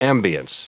Prononciation du mot ambiance en anglais (fichier audio)
Prononciation du mot : ambiance